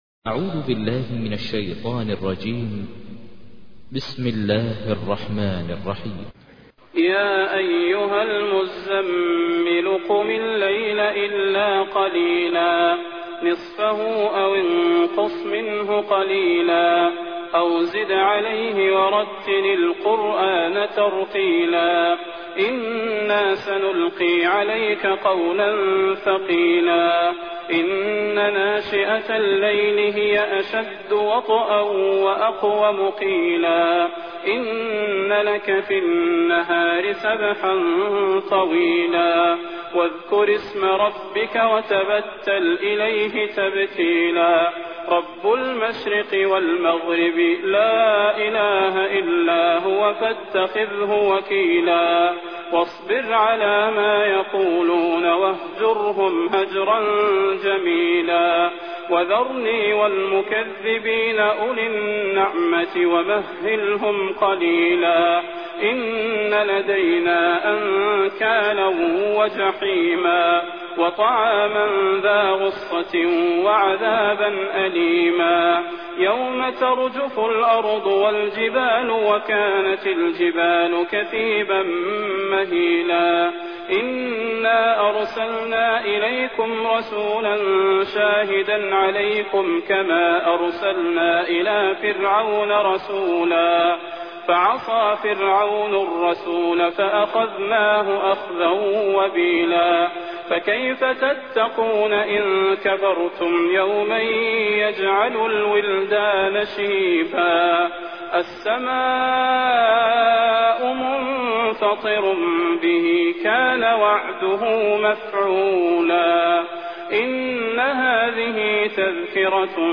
تحميل : 73. سورة المزمل / القارئ ماهر المعيقلي / القرآن الكريم / موقع يا حسين